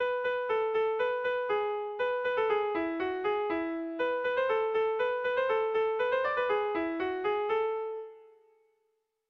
Bertso melodies - View details   To know more about this section
Erromantzea
ABD